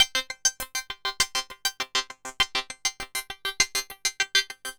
tx_synth_100_thinarps_CG.wav